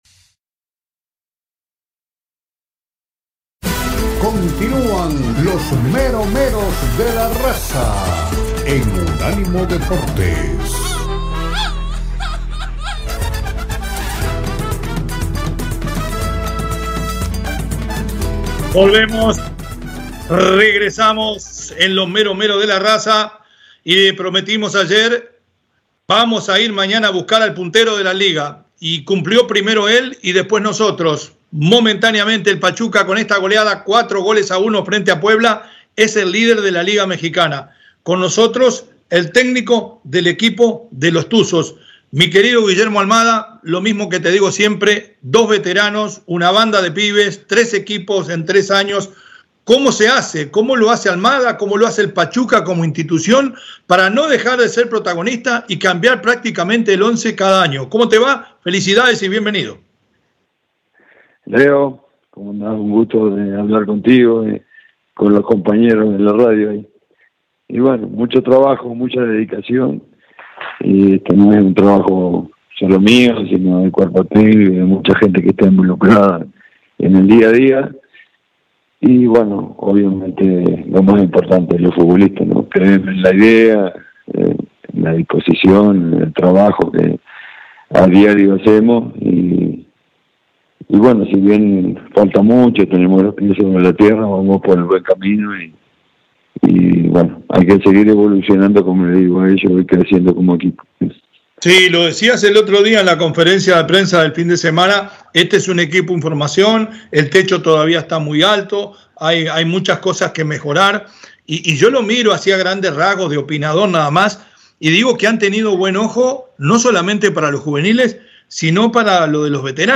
Guillermo Almada, director técnico de Pachuca, platicó con Los Meros Meros De La Raza sobre su futuro con el equipo tuzo y qué tan cercana podría ser la posibilidad de volver a estar en el radar de la Selección Mexicana.